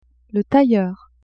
Tailleur: [tajoer]
tailleur.mp3